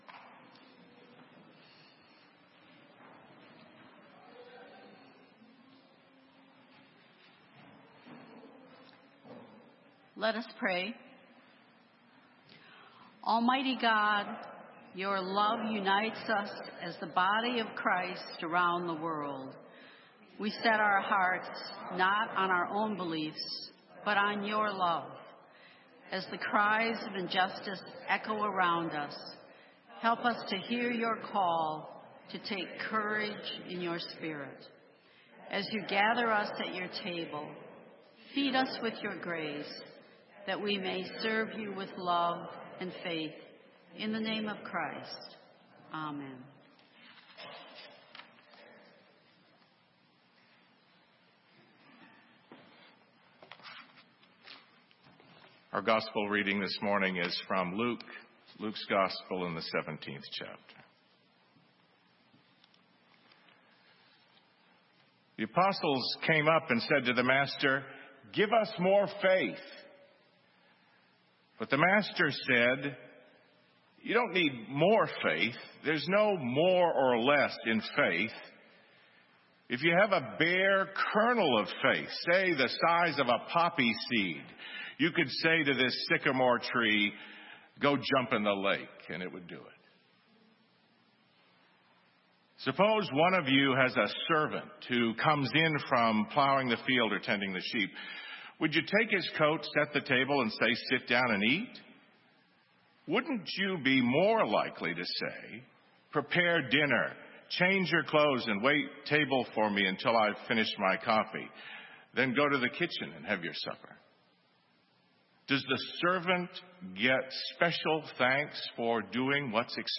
Sermon:Mustard Seed Faith - St. Matthews United Methodist Church